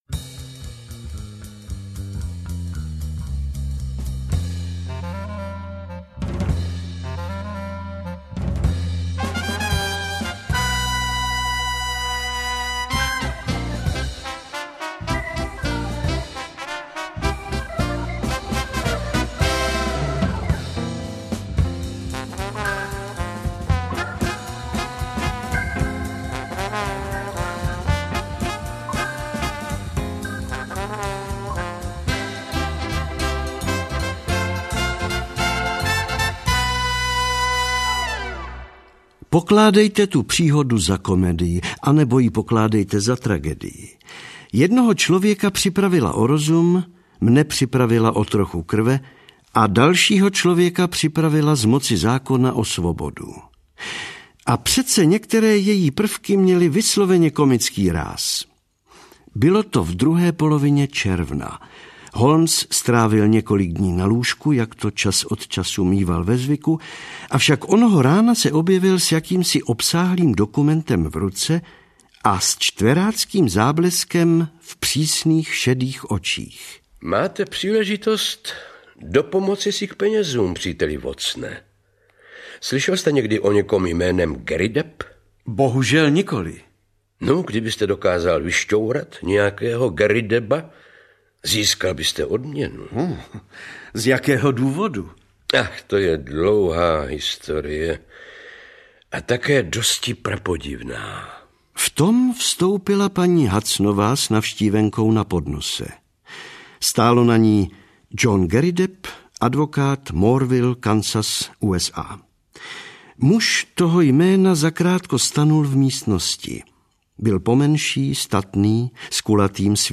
Celý popis Rok vydání 2015 Audio kniha Ukázka z knihy 199 Kč Koupit Ihned k poslechu – MP3 ke stažení Potřebujete pomoct s výběrem?